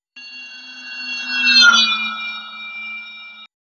The following audio signals represent pass-by signals of a railway wheel, evaluated including 12 and 58 modes, respectively. The excitation is at Node 2.